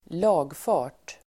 Ladda ner uttalet
lagfart substantiv, entry into the land register Uttal: [²l'a:gfa:r_t] Böjningar: lagfarten, lagfarter Definition: inregistrering (vid tingsrätten) att någon blivit ägare till fast egendom, t ex en tomt